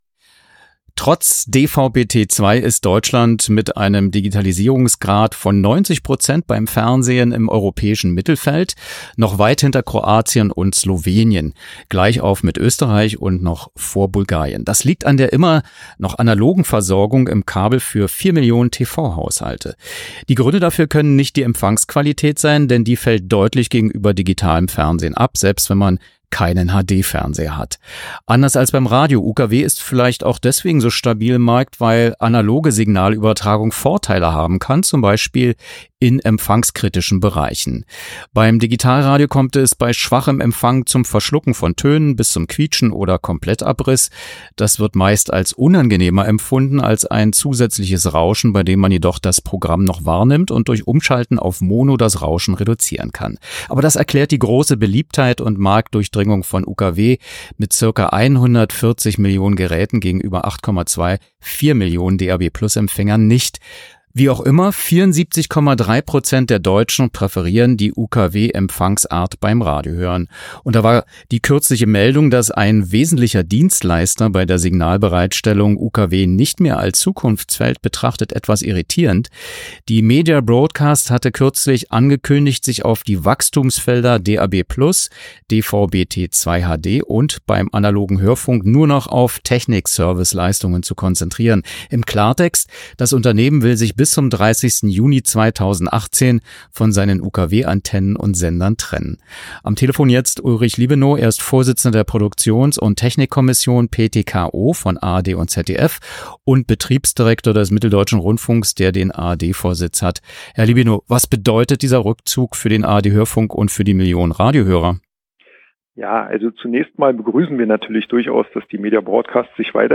Telefoninterview zur Zukunft von UKW (Langfassung)